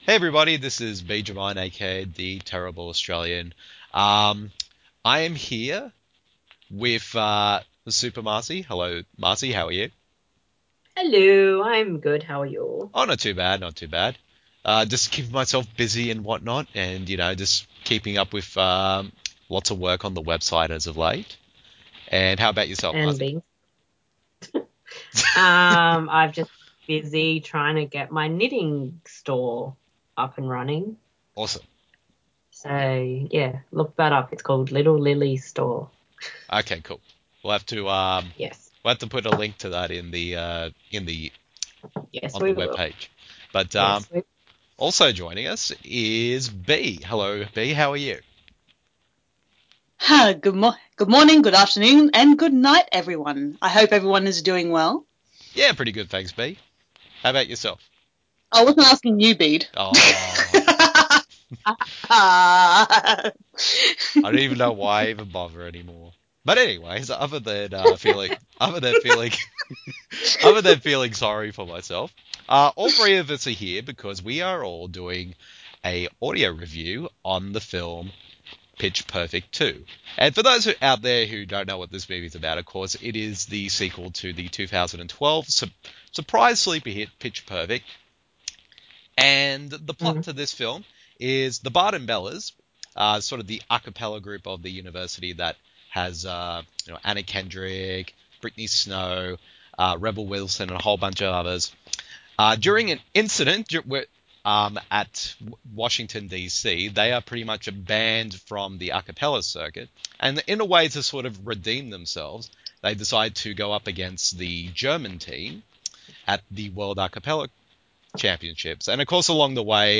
The following review in an audio format, as a back and forth discussion between the three of us.